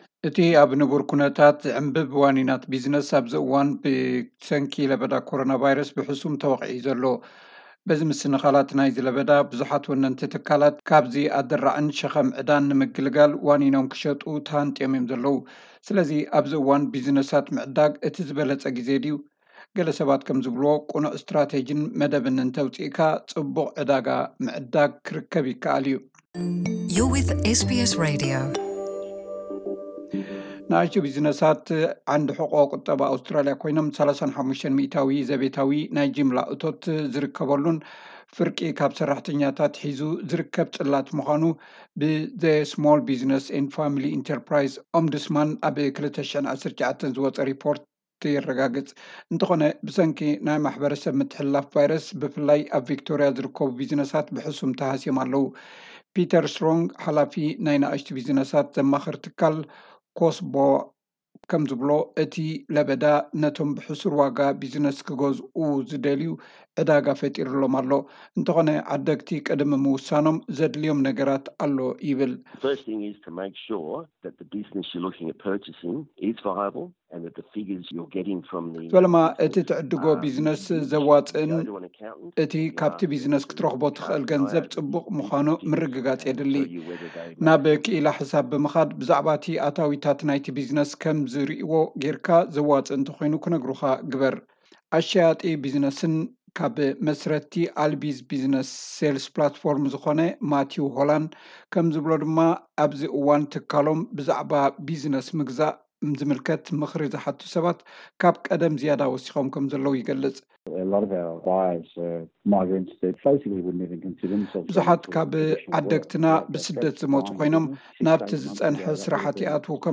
ለበዳ ኮሮና ቫይረስ ንነኣሽቱ ቢዝነሳት ኣብ ሰንከልከል ኣውዲቕሉ ኣብዘሎ እዋን፡ ገለ ዓቕሎም ዝጸበቦም ወነንቲ ቢዝነስ ናብ መሸጣ ይጓየዩ ኣለዉ። ኣብዚ እዋን ቢዝነስ ምግዛእ የዋጽእዶ? ቅኑዕ ስትራተጂን መደብን ኣውጺእካ ዝግበር ዕድጊ ከዋጽእ ከምዝኽእል ገለ ኪኢላታት ይዛረቡ።